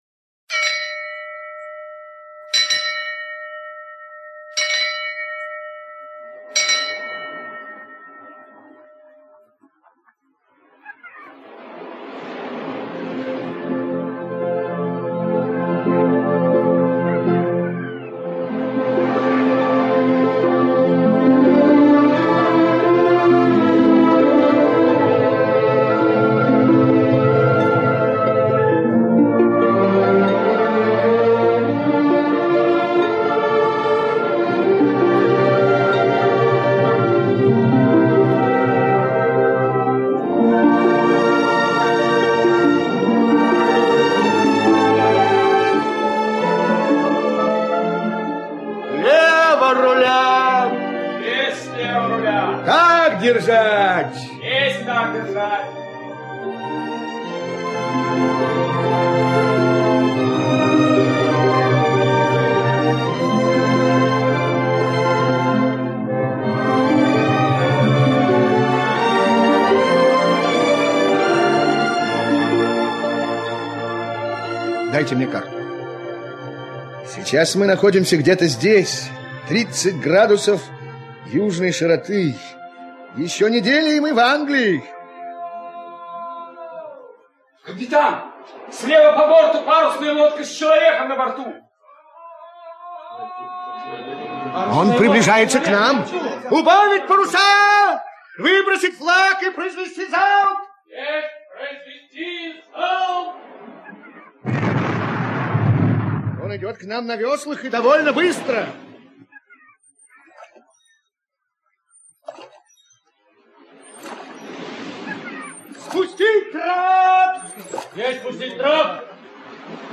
Гулливер в стране лилипутов - аудиосказка Свифта - слушать онлайн